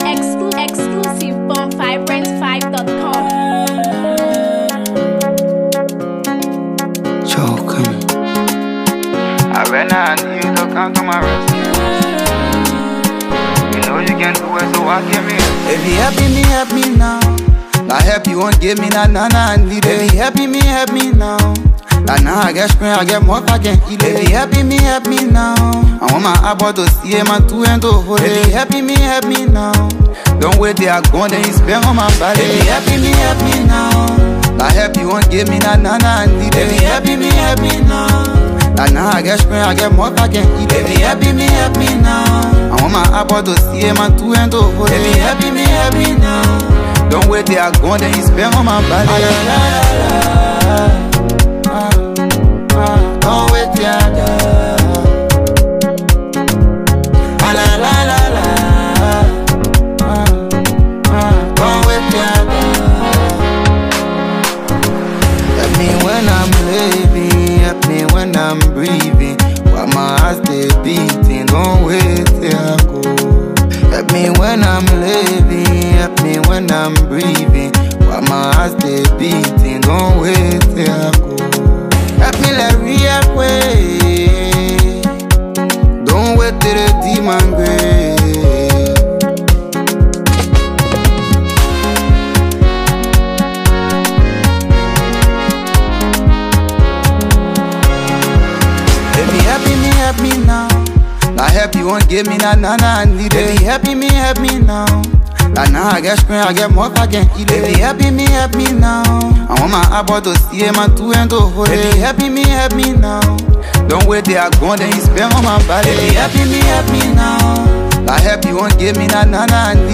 With heartfelt vocals and soul-stirring lyrics